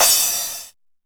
TR 909 Cymbal 02.wav